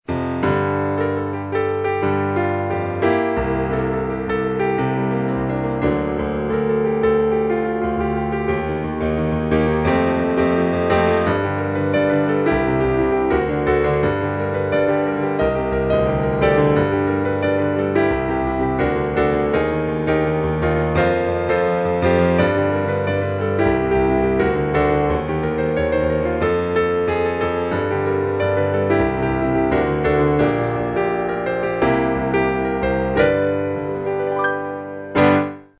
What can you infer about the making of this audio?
I just woke up and started playing it. It's fun and has potential, I think, but I don't feel like working on it right now, so this is a "just fooling around" take, rough and short.